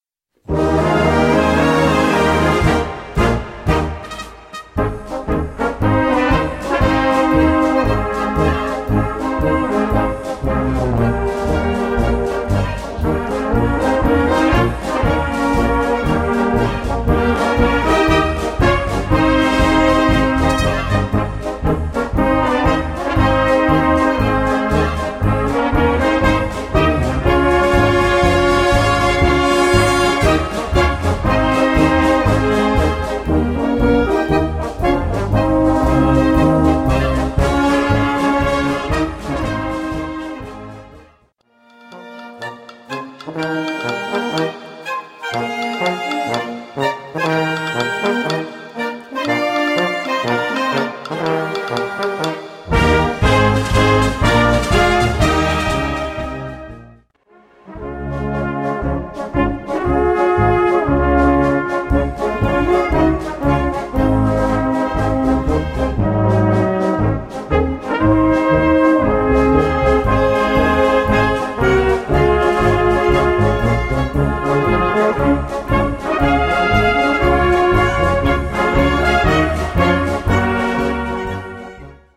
Blasorchester